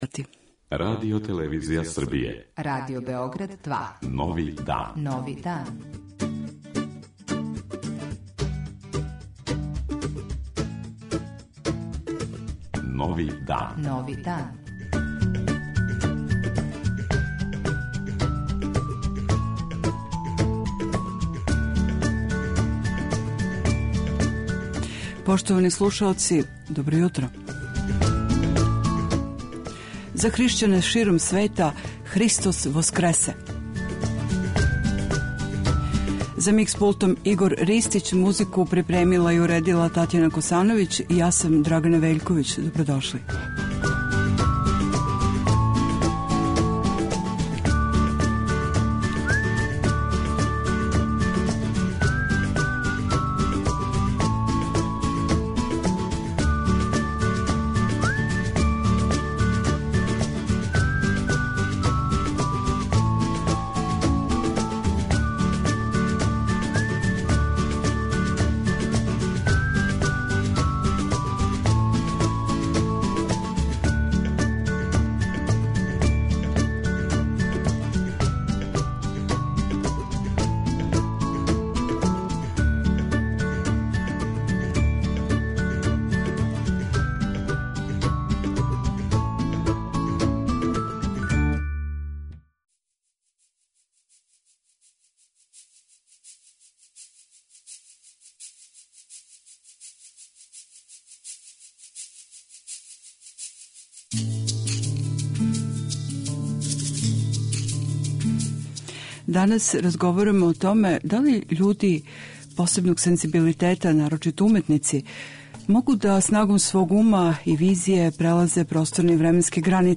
Гост изненађења посетиће нас на рано ускршње јутро у Студију 2 Радио Београда. Тема јутра је како настаје уметничко дело и има ли мисао границе, могу ли уметници будни да путују кроз време и простор и шта виде и доносе са тих путовања.